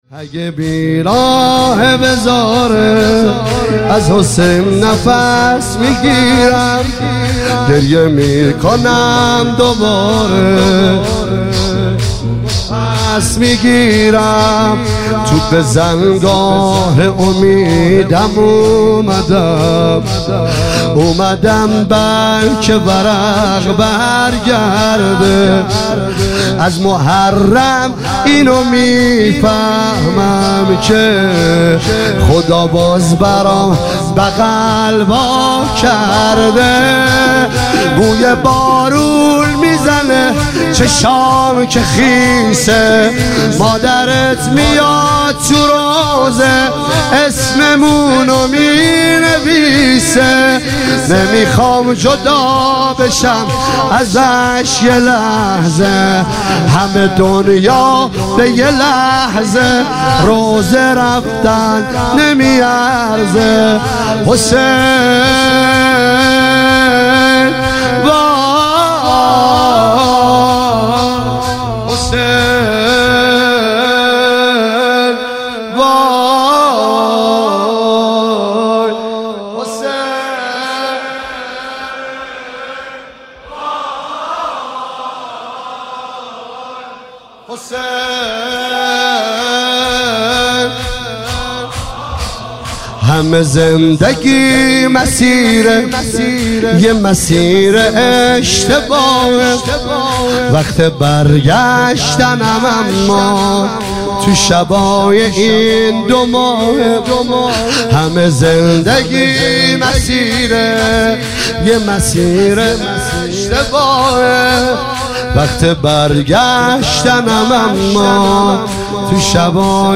شور اگه بی راهه بذاره از حسین نفس می گیرم